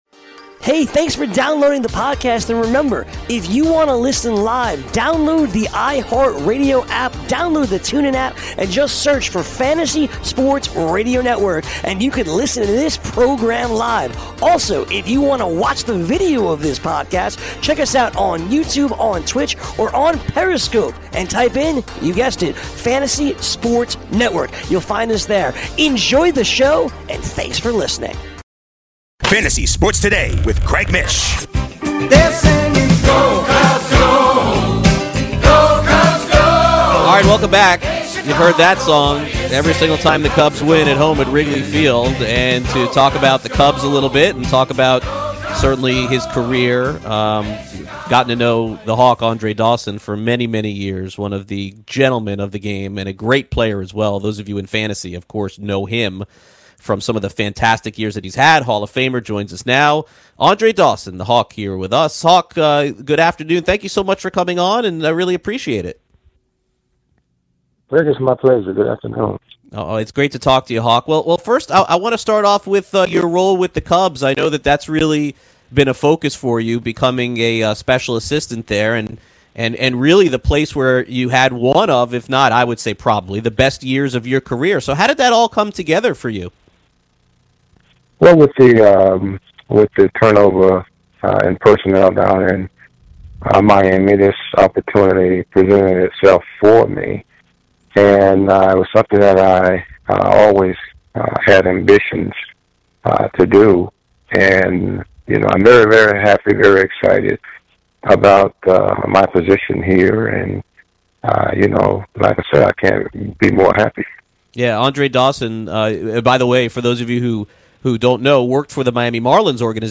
Former Cubs All-Star Andre Dawson joins the show to talk about his time with the Cubbies, and how he feels the Northside team is fairing so far in 2019.